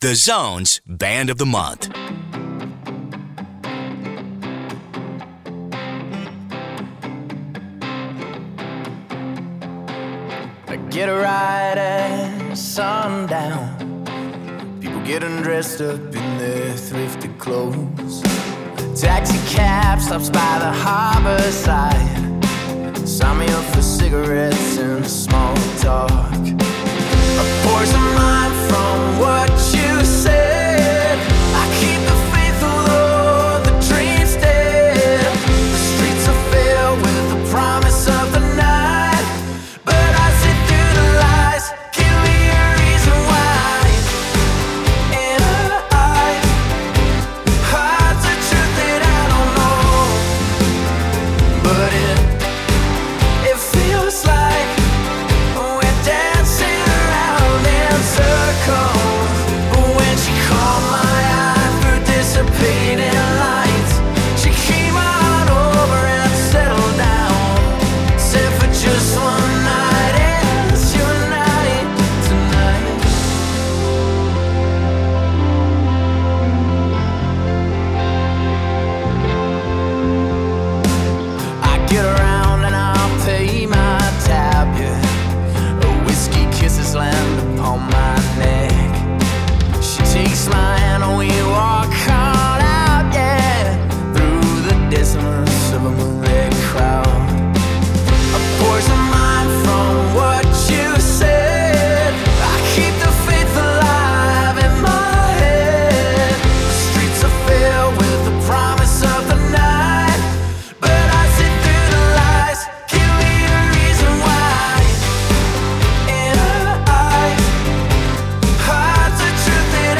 Lead Guitar & Lead Vocals
Drums
Keys, Guitar and Backing Vocals
Bass and Backing Vocals